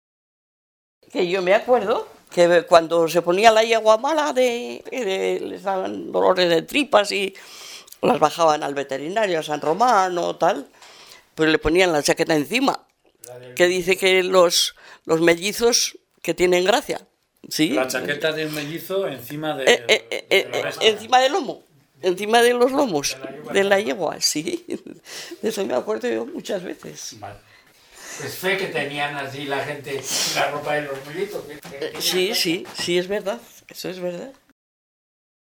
Clasificación: Supersticiones
Lugar y fecha de recogida: Logroño, 8 de junio de 2005